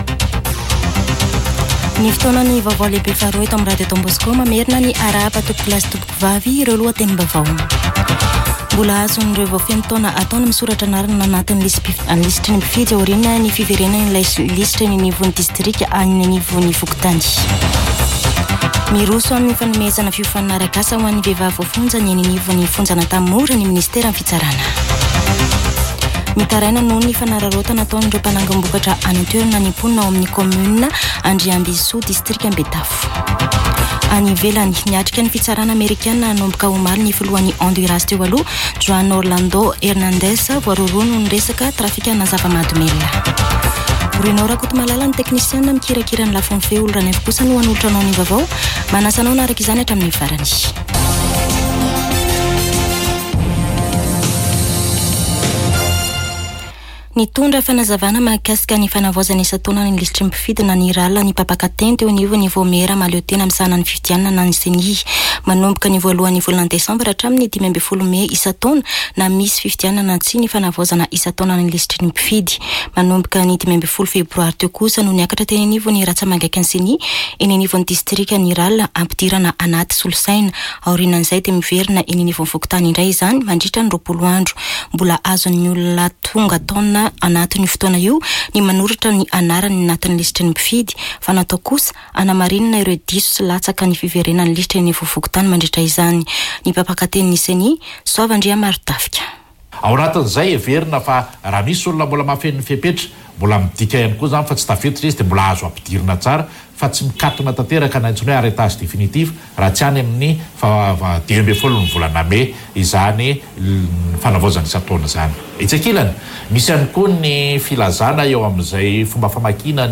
[Vaovao antoandro] Alarobia 21 febroary 2024